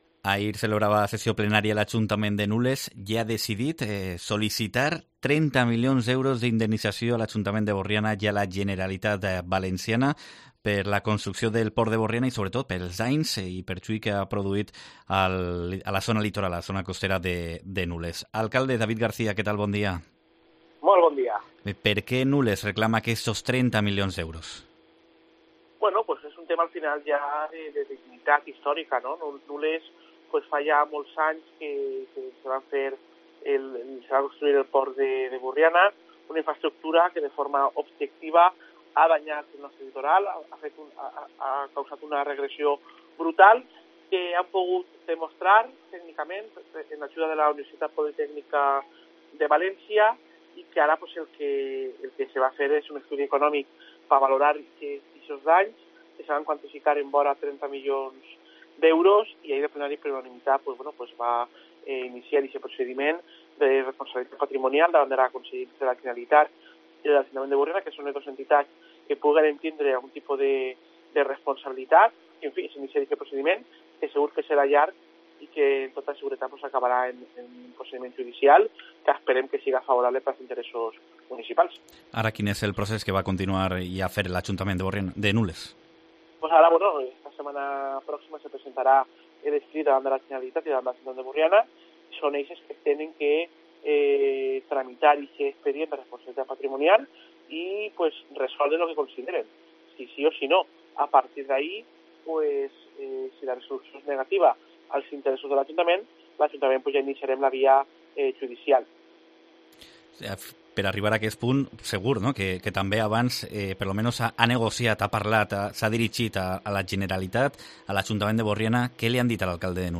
El alcalde de Nules, David García, explica en COPE la reclamación y a qué se destinaría la indemnización.